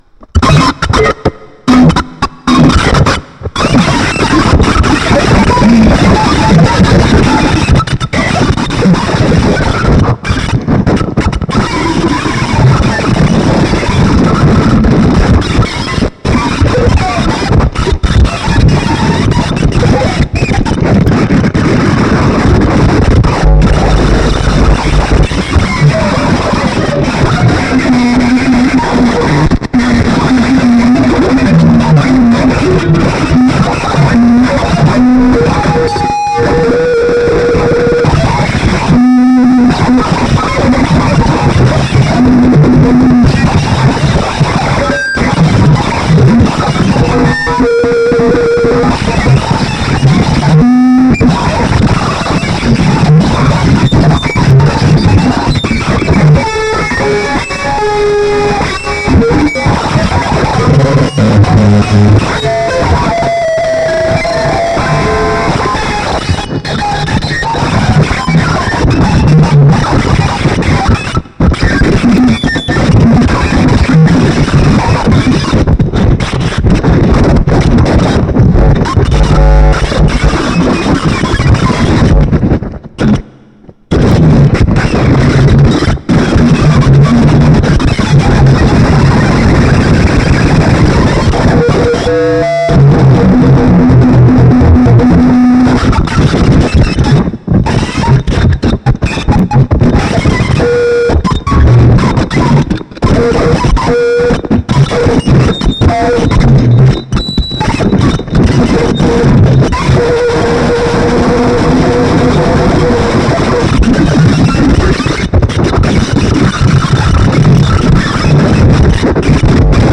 live on radio in Orel